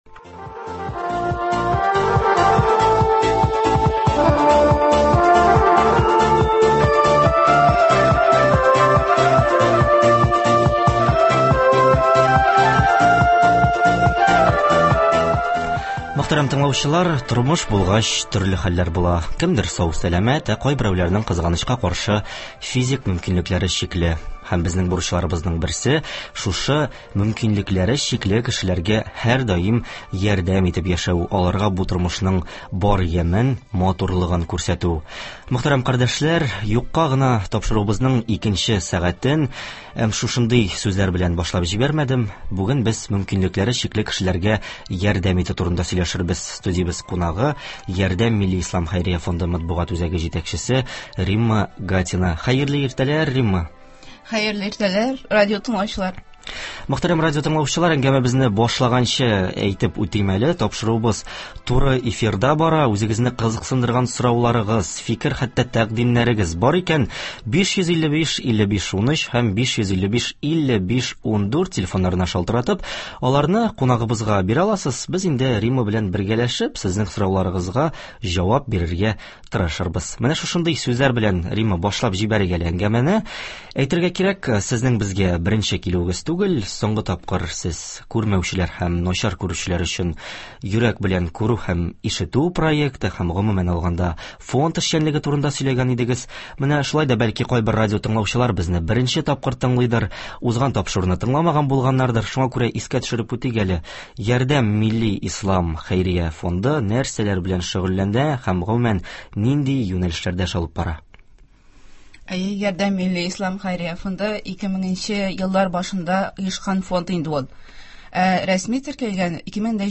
Безнең бурычларыбызның берсе – шушы мөмкинлекләре чикле кешеләргә ярдәм итү, аларга бу тормышның бар ямен, матурлыгын күрсәтү. Бүген без туры эфирда мөмкинлекләре чикле кешеләргә ярдәм итү турында сөйләшербез.